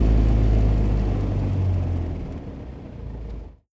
engine-cut.wav